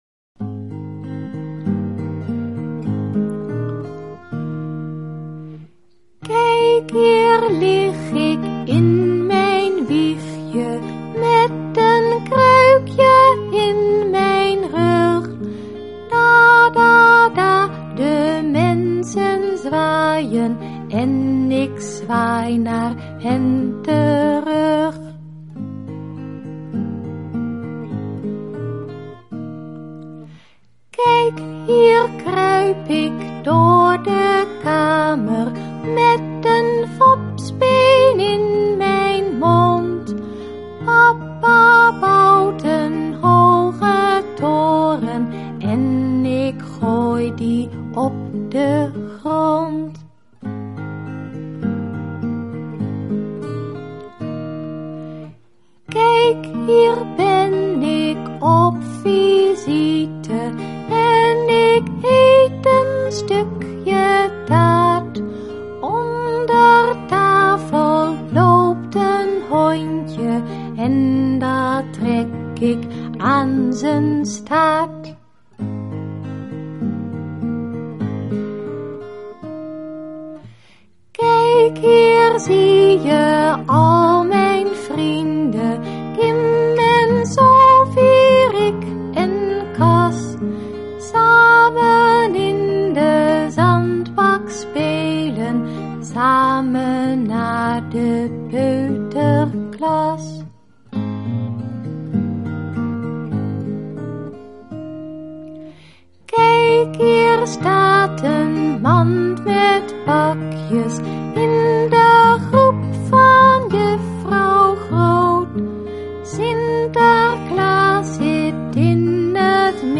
liedjes voor peuters en kleuters
Zang
begeleiding